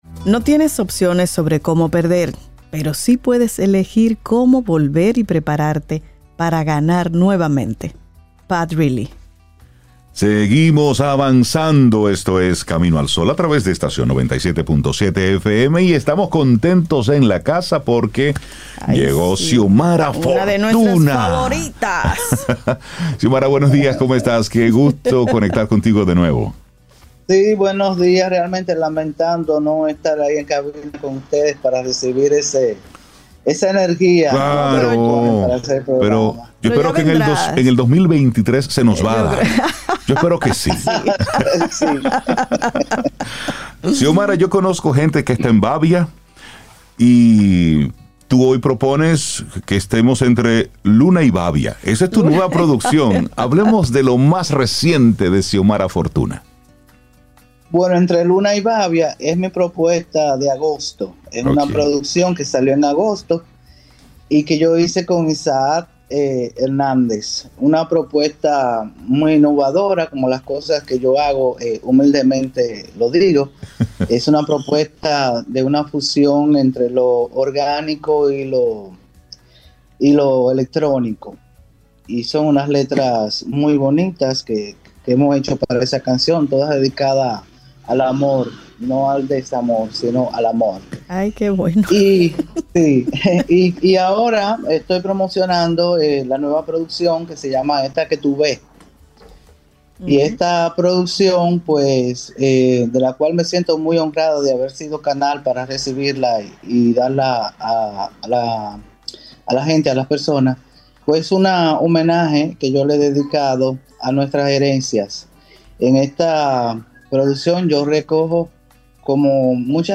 Entrevista | «Entre Luna y Babia»: el nuevo disco de Xiomara Fortuna